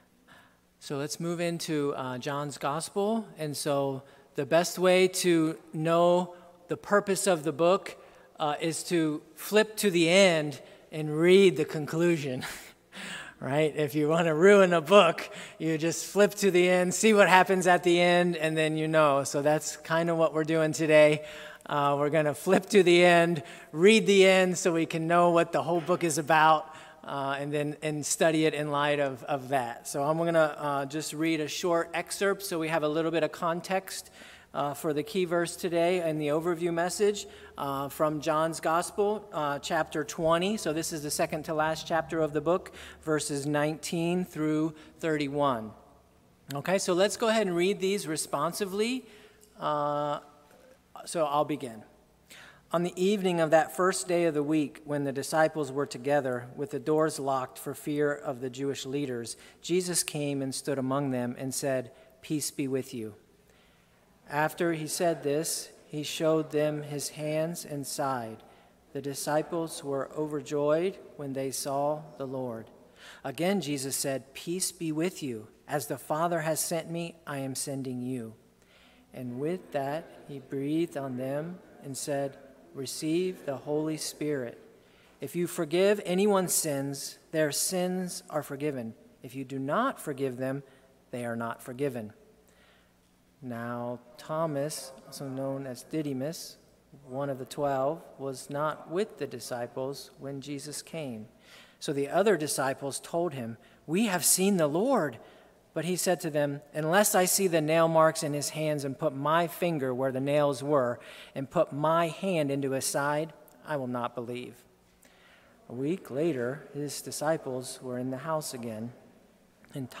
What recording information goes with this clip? Sunday-Worship-8-30-20.mp3